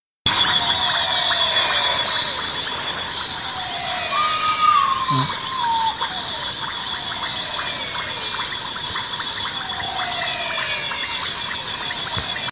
Common Potoo (Nyctibius griseus)
Life Stage: Adult
Location or protected area: Las Varillas
Detailed location: Camino Rural
Condition: Wild
Certainty: Photographed, Recorded vocal